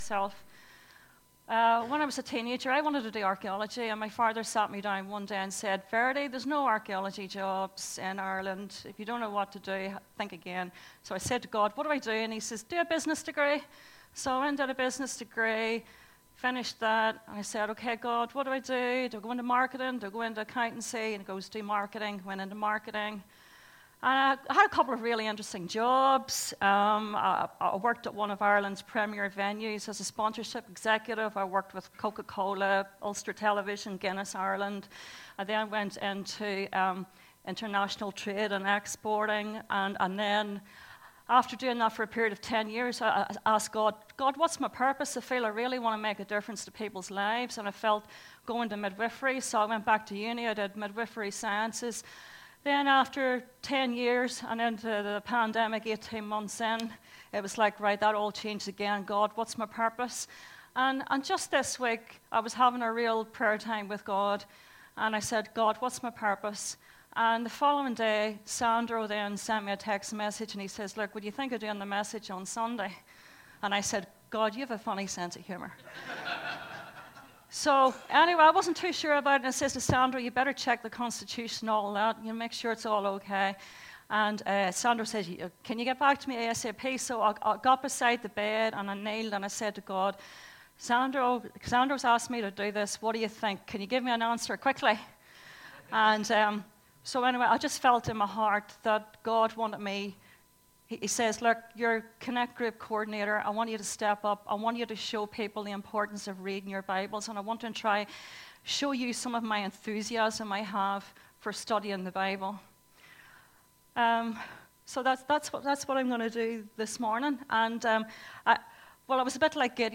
2025 • 25.02 MB Listen to Sermon Download this Sermon Download this Sermon To download this sermon